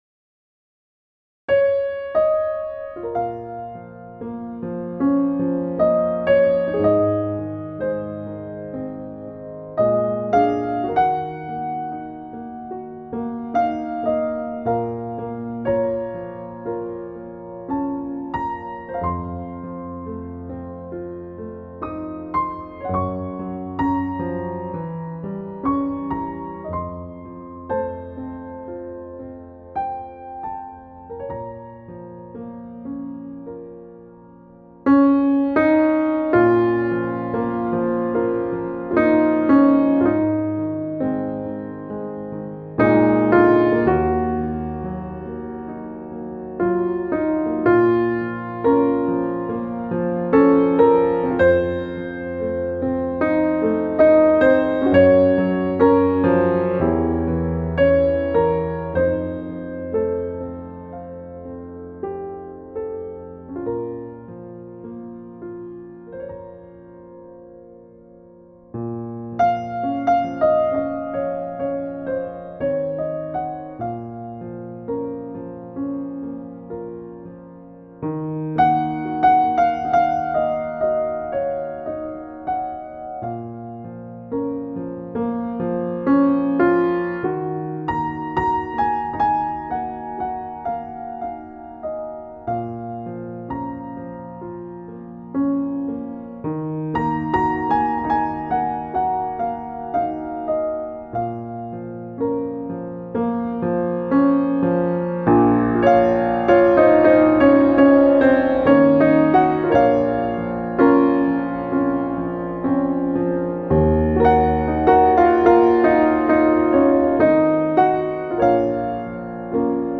سبک : بی کلام